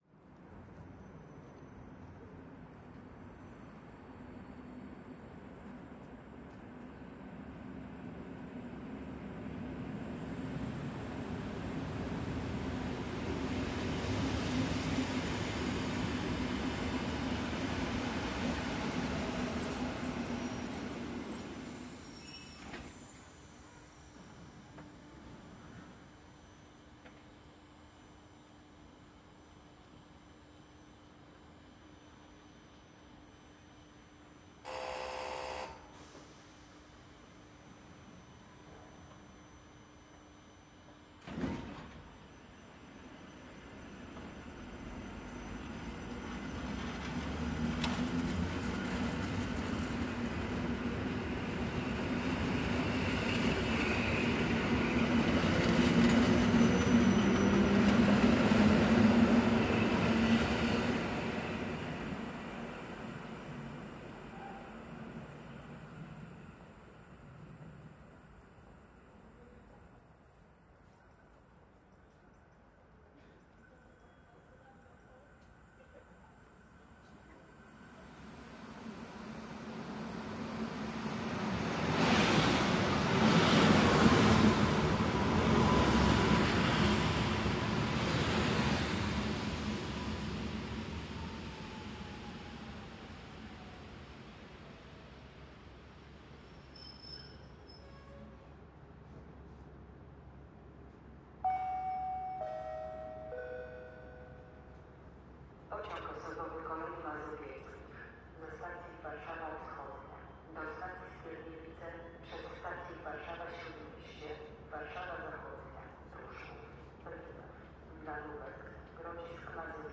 火车, 在车站经过, 波兰, 刹车, 车门打开关闭, 警告嗡嗡声, EN57和Pesa Elf, 波兰语公告
描述：火车，在车站经过，波兰，刹车，门开关，警告嗡嗡声，EN57和Pesa精灵，波兰语公告
Tag: 公告 火车 波兰 车站